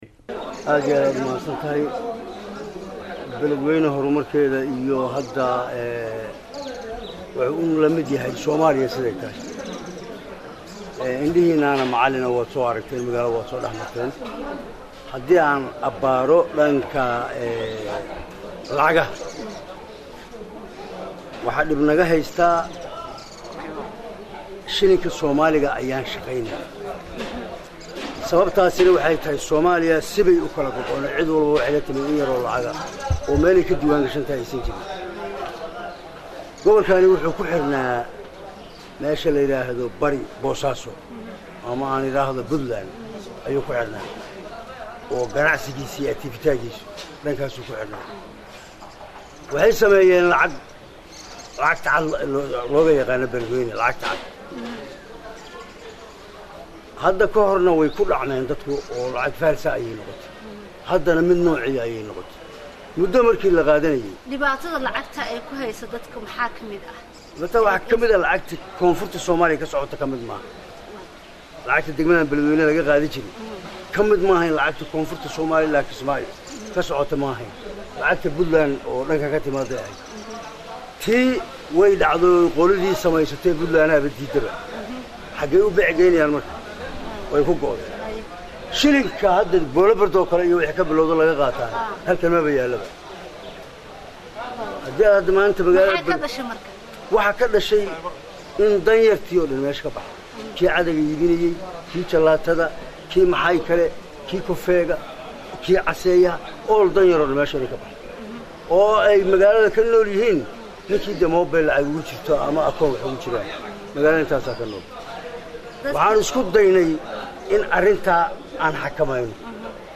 Guddoomiyaha Gobolka Hiiraan Cali jayte Cismaan oo la hadlay Warbaahinta Qaranka ayaa sheegay in Gobolka Hiiraan ay ka taagayn tahay